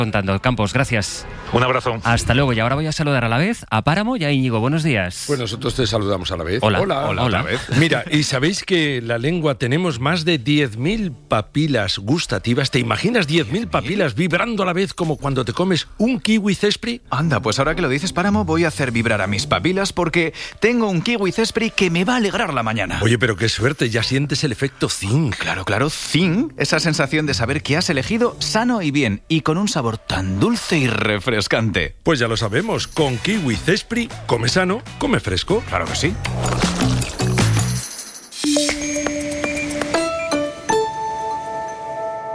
Mención de publicidad de kiwis Zespri